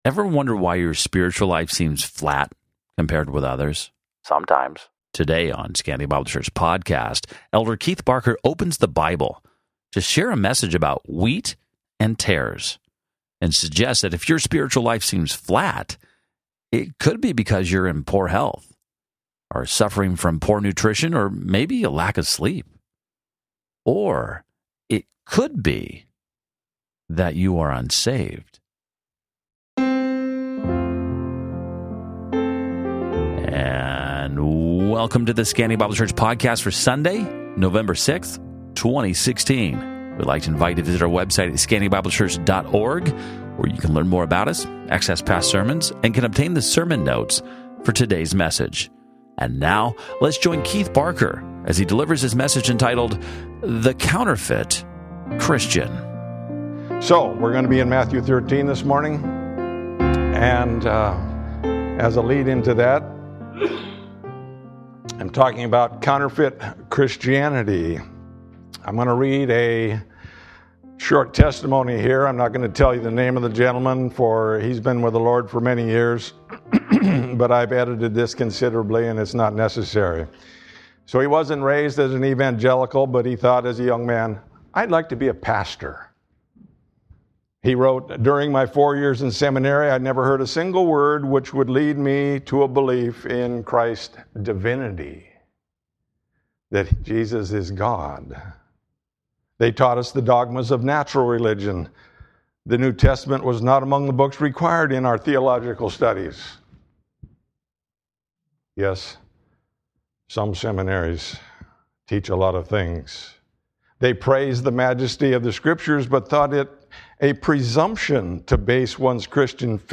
Sermon Notes Date